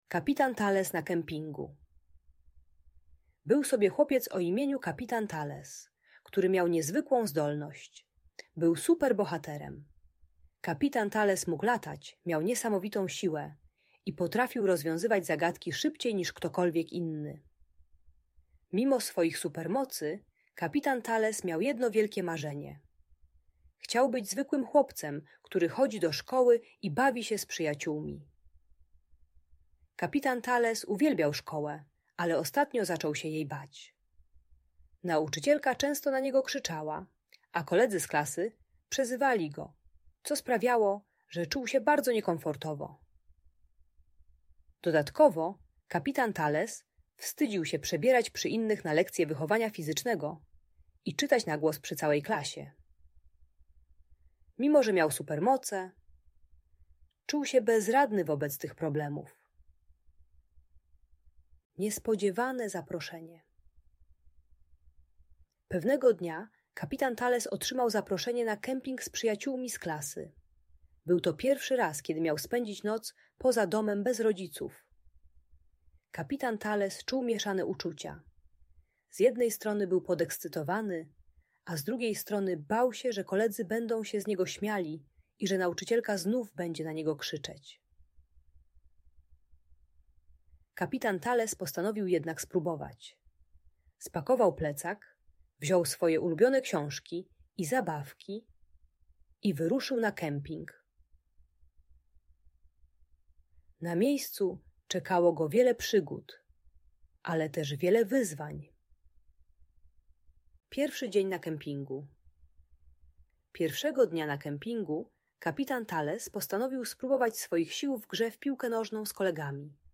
Historia Kapitana Talesa na Kempingu - Opowieść o Odwadze i Przyjaźni - Audiobajka